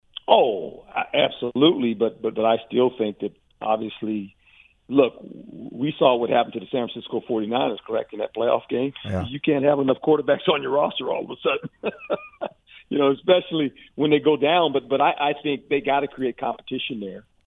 Former head coach and current ESPN analyst Herm Edwards, who most recently coached with Arizona State, joined Sport Scene on Wednesday to discuss some major storylines across the NFL ahead of next week’s Super Bowl.